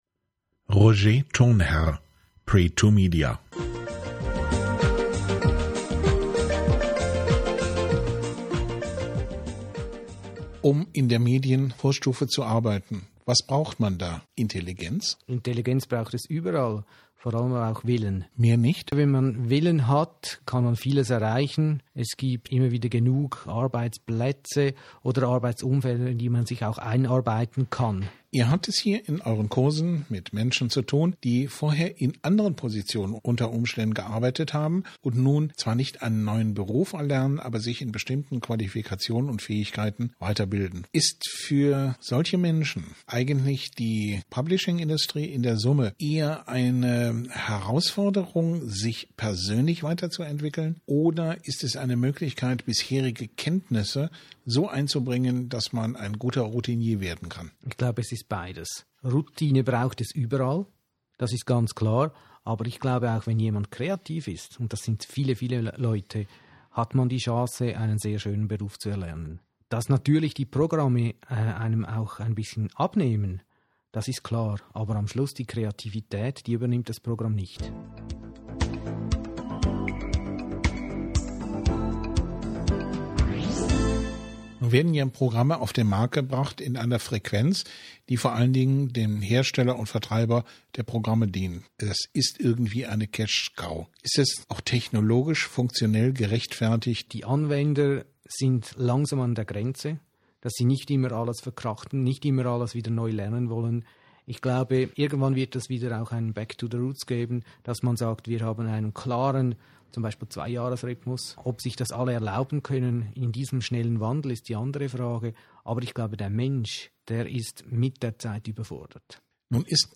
Interview, ca. 5 Minuten